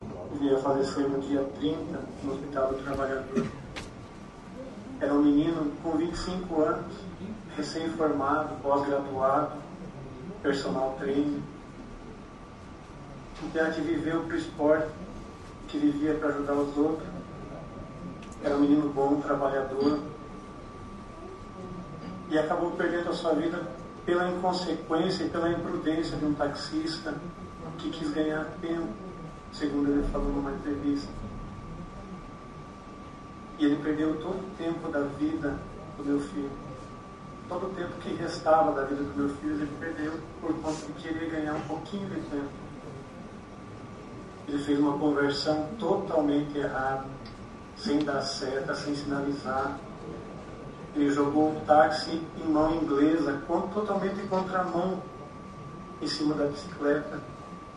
O depoimento emocionado